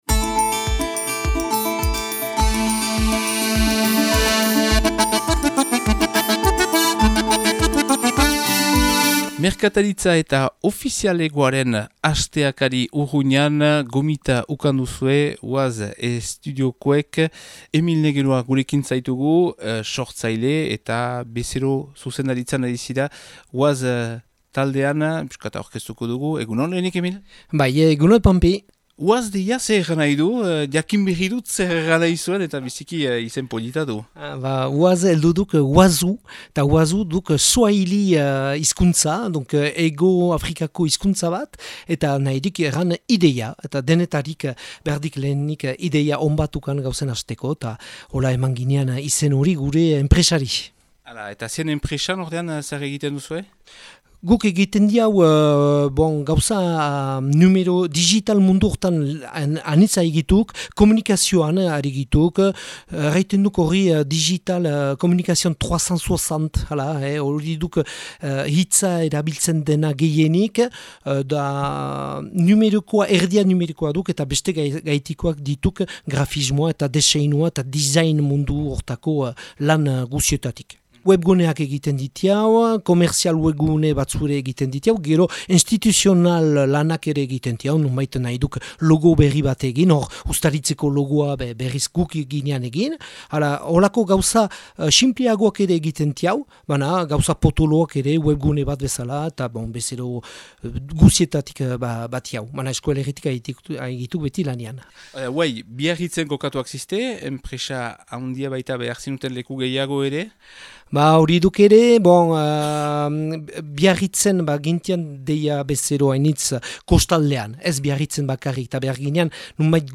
Filipe Aramendi auzapezarekin ere hitz egin dugu